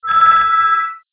windows_ringin.wav